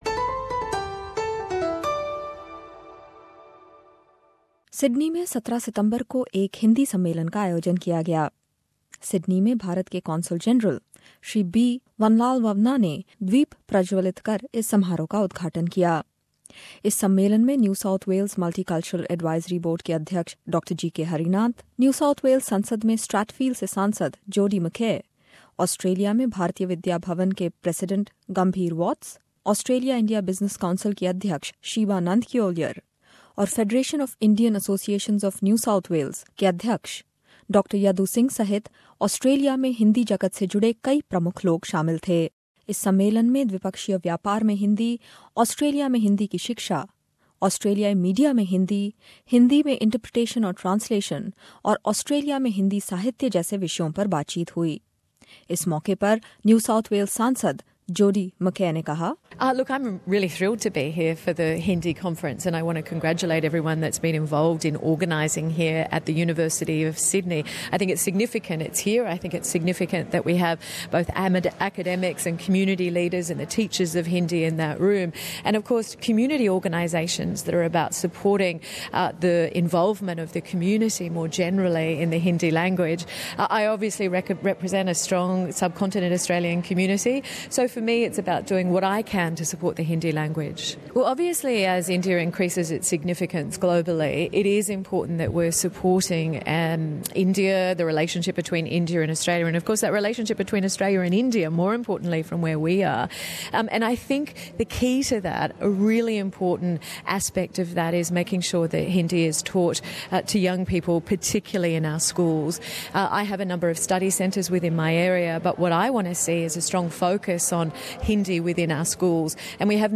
Hindi lovers and people from various fields who work in areas related to Hindi in Australia were at this event. We were there too.